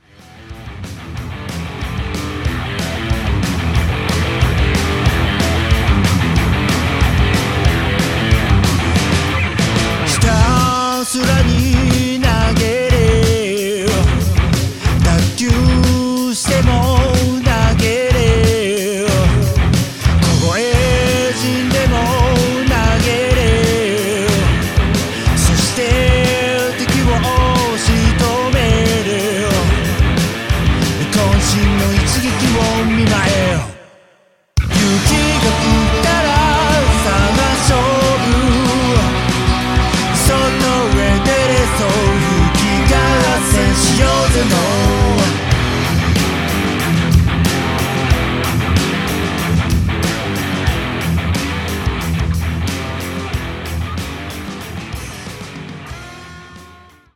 ロックバンド
ちょっぴりいねよな土着ロック 聴いてみませんか？